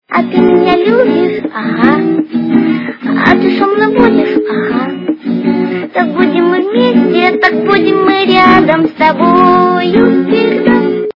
качество понижено и присутствуют гудки
реалтон детская песенка